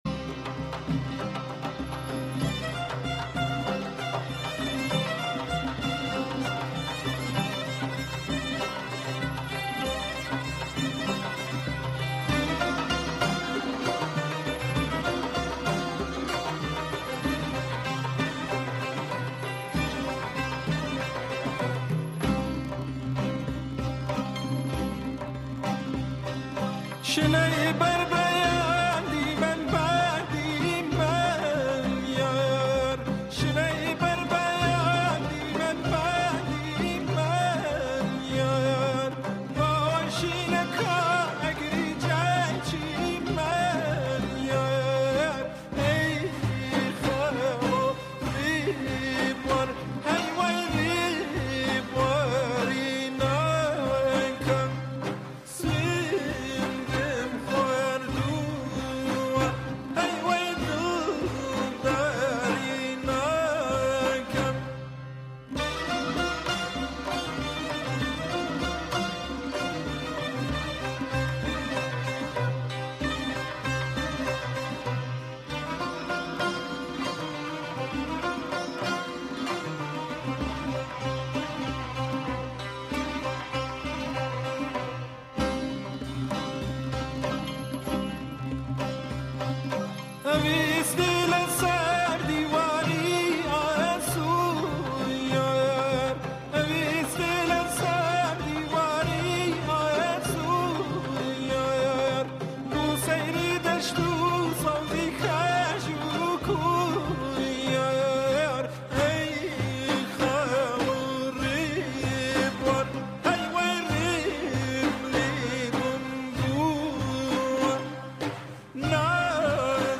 گۆرانییەکی کوردی به ناوی یار